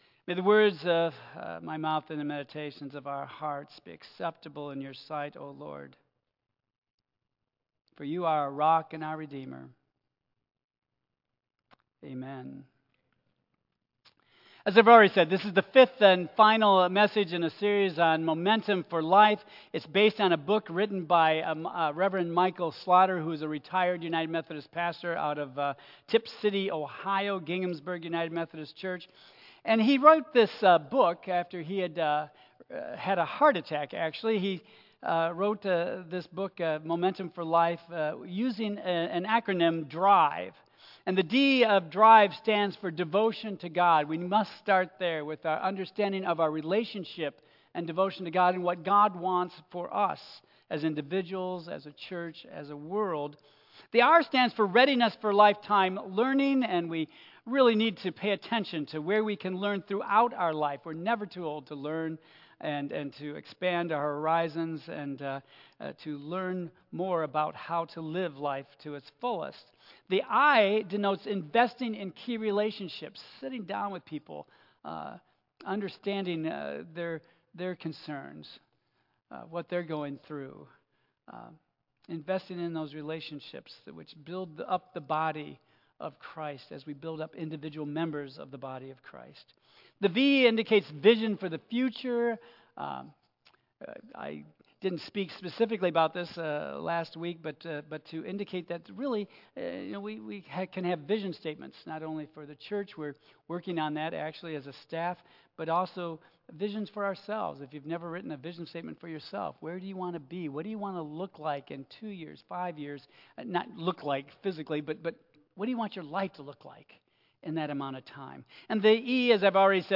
Tagged with Michigan , Sermon , Waterford Central United Methodist Church , Worship Audio (MP3) 7 MB Previous Vision for Vitality Next Can You Love When It's Difficult?